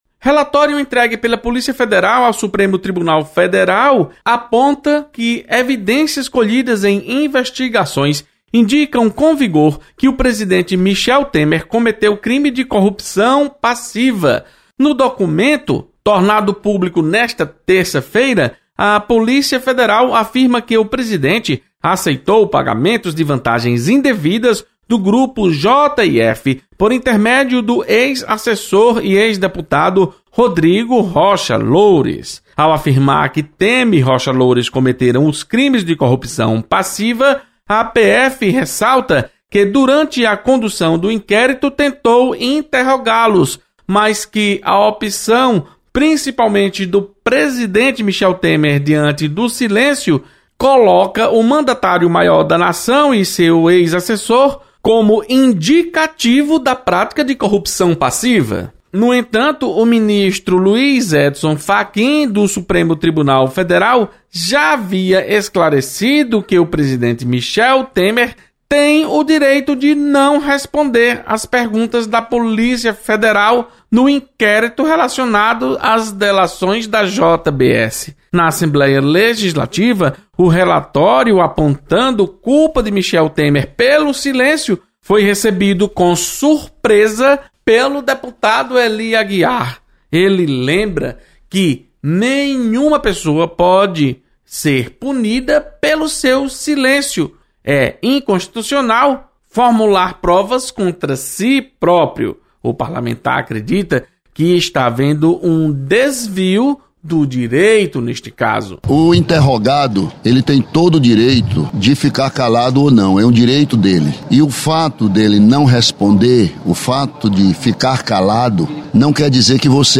Deputado Ely Aguiar estranha relatório acusando o presidente Michel Temer por seu silêncio em questionamentos da Polícia Federal. Repórter